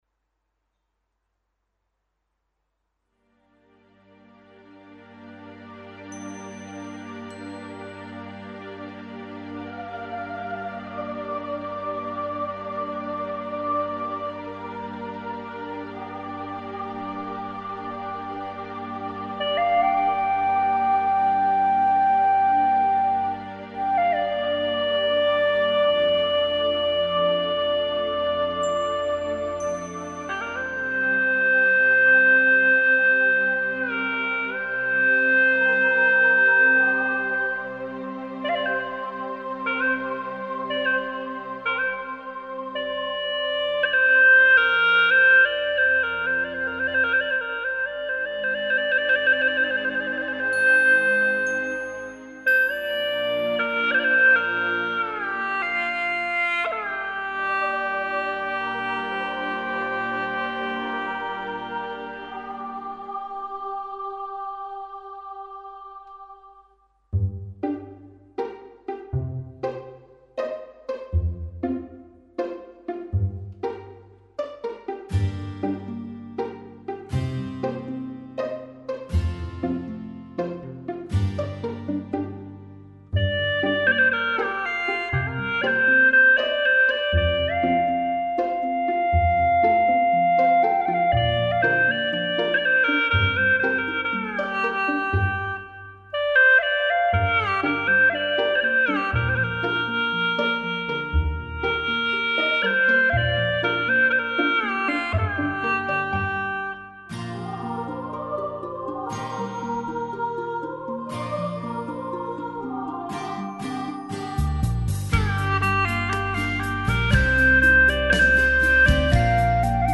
调式 : 降B 曲类 : 独奏